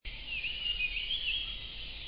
烏線雀鶥 Alcippe brunnea brunnea
錄音地點 南投縣 鹿谷鄉 溪頭
錄音環境 森林
雄鳥歌聲
收音: 廠牌 Sennheiser 型號 ME 67